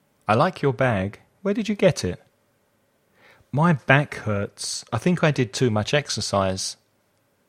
Pronunciación
/k/ - back                /g/ - bag